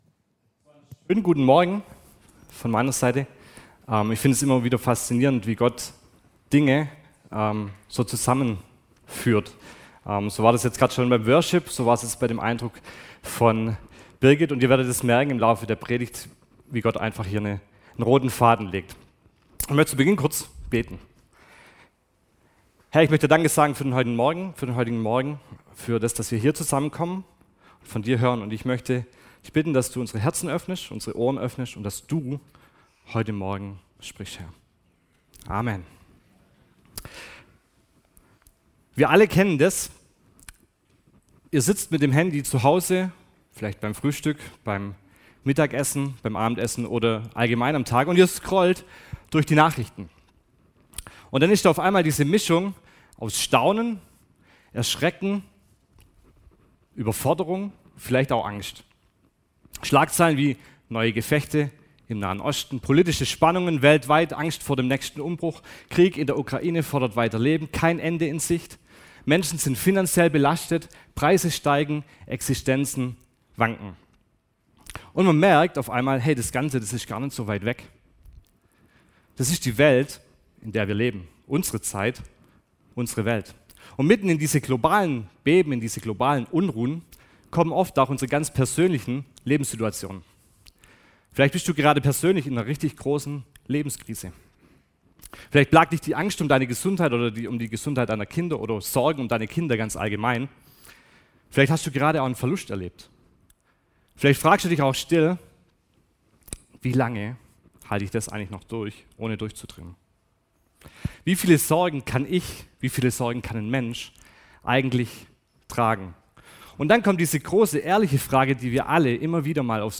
Dienstart: Gottesdienst - Sonntagmorgen